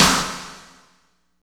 49.04 SNR.wav